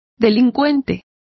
Complete with pronunciation of the translation of delinquent.